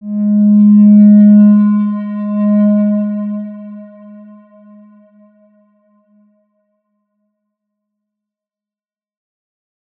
X_Windwistle-G#2-mf.wav